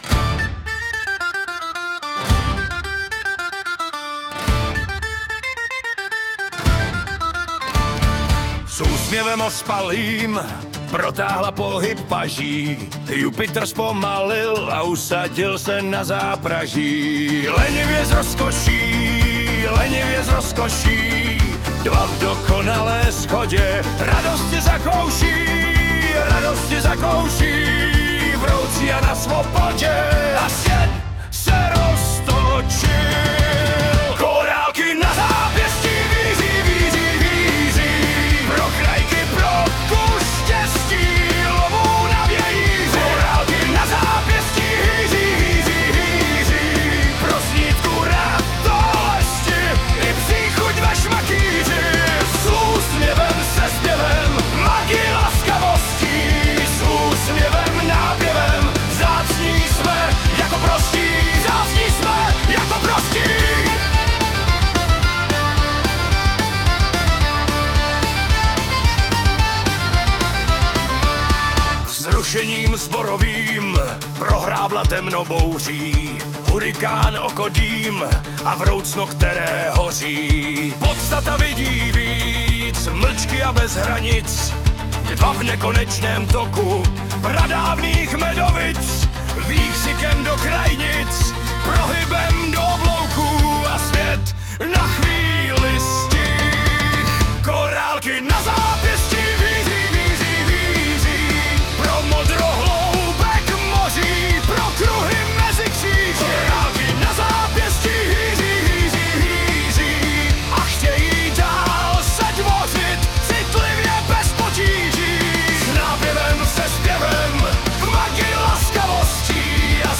Nemám slov... čistá energie .